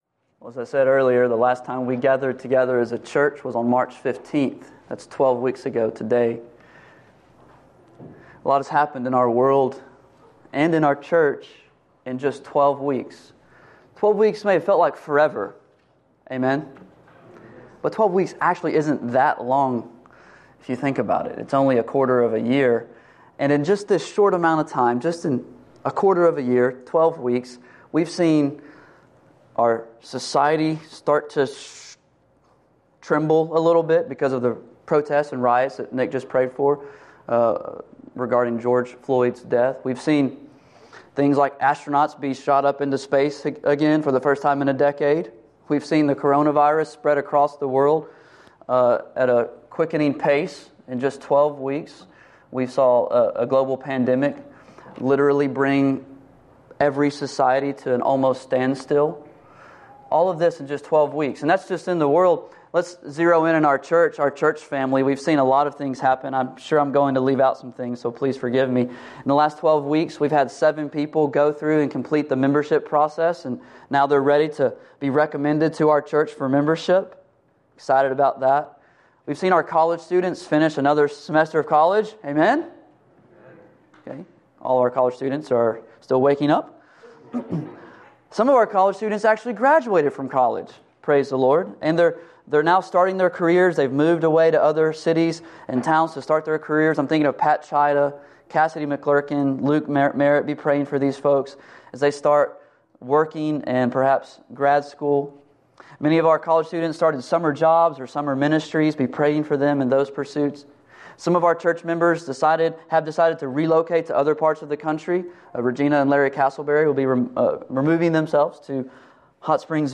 Much Has Happened Since March 15th We last gathered as a church on March 15th, twelve weeks ago today.